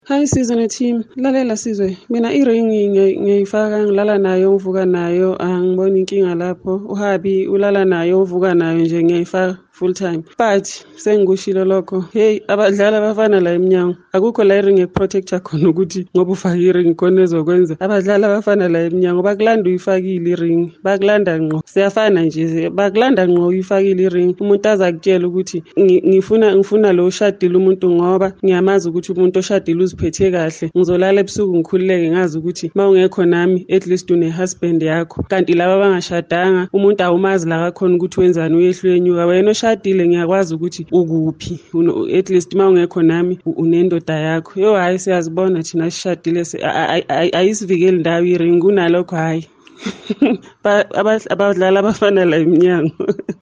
Here’s what Kaya Drive listeners shared: